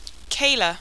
Pronunciation: Kayla - kay-la